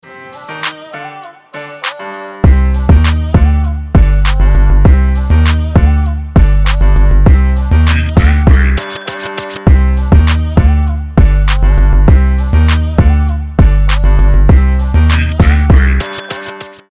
• Качество: 320, Stereo
басы
качающие
биты
Качёвый биток